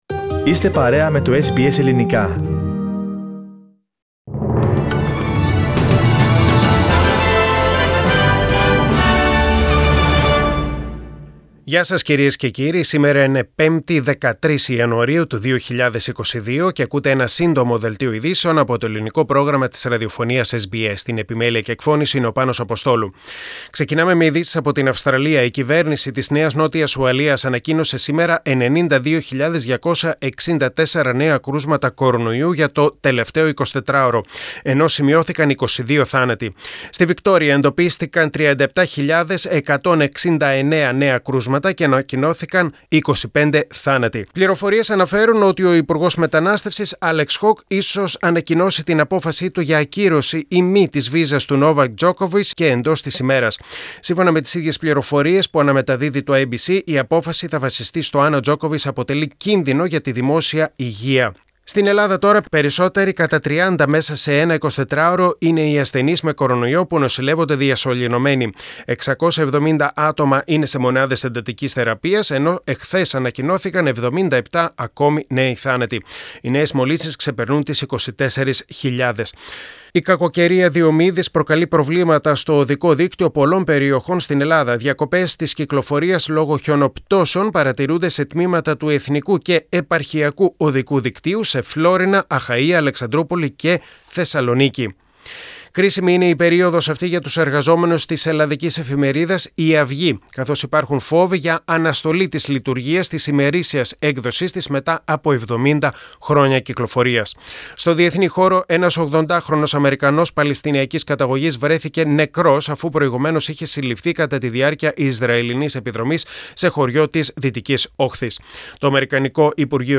Short news bulletin in Greek: Thursday 13.1.2022